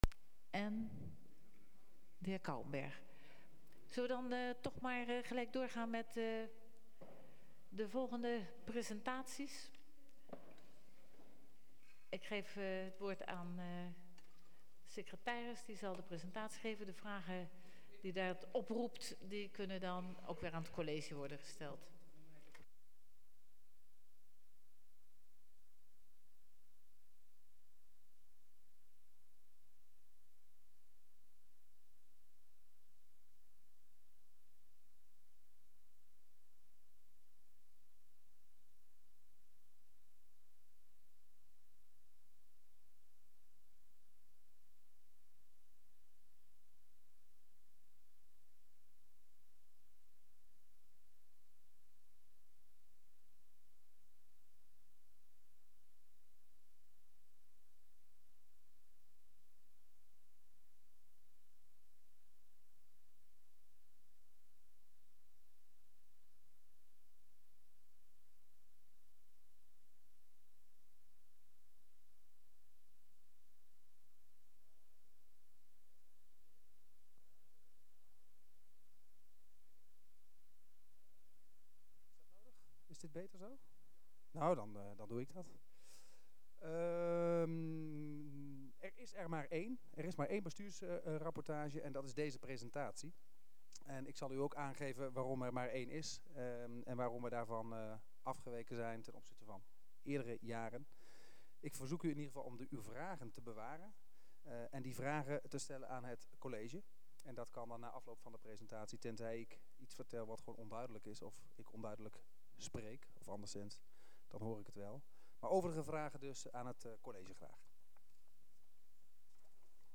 Gemeenteraad 28 juni 2012 21:45:00, Gemeente Goirle
Download de volledige audio van deze vergadering